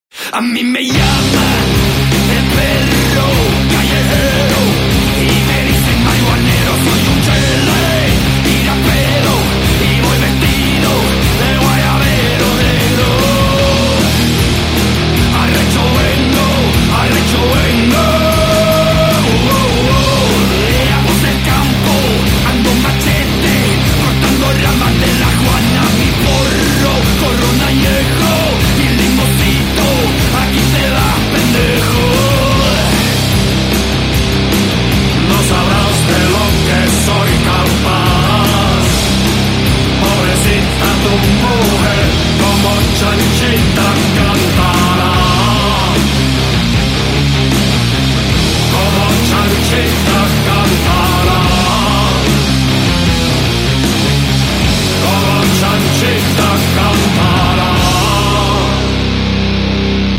• Качество: 128, Stereo
гитара
мужской голос
громкие
жесткие
Испанский рок